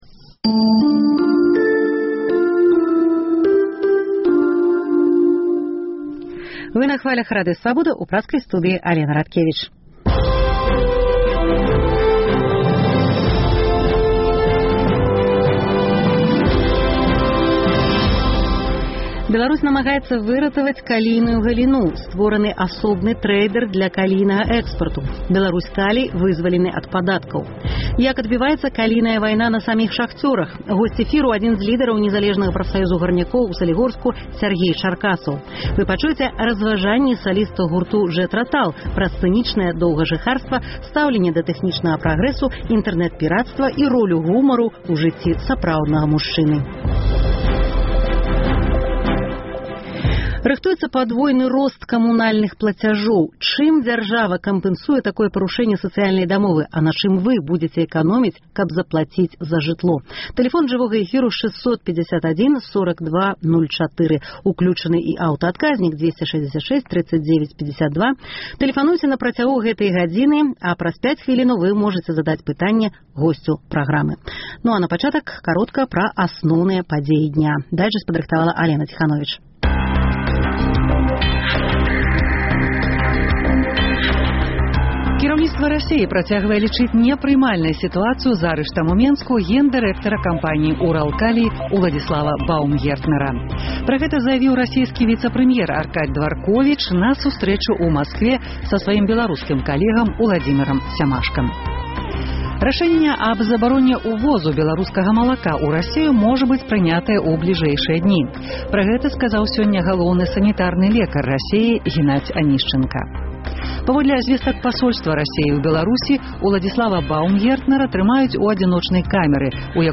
Вы пачуеце таксама разважаньні саліста гурту Jethro Tull Іэна Андэрсана пра сцэнічнае доўгажыхарства, стаўленьне да тэхнічнага прагрэсу, інтэрнэт-пірацтва і ролю гумару ў жыцьці сапраўднага мужчыны.